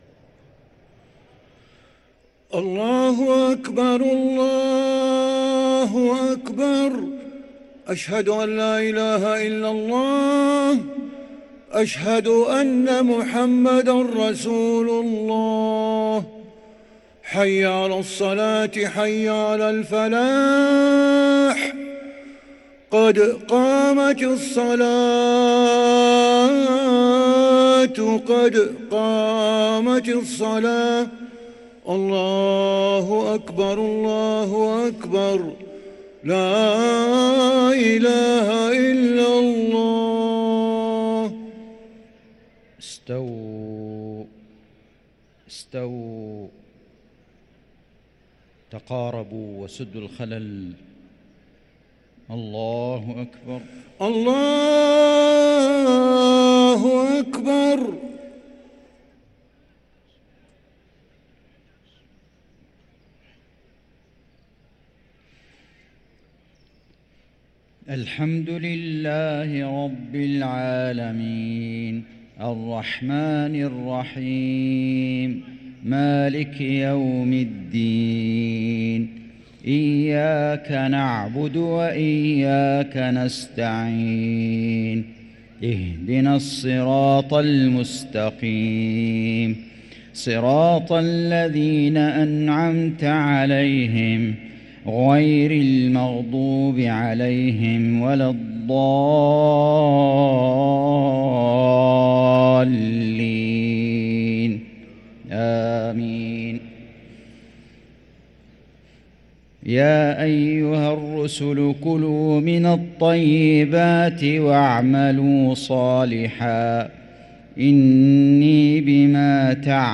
صلاة المغرب للقارئ فيصل غزاوي 6 رمضان 1444 هـ
تِلَاوَات الْحَرَمَيْن .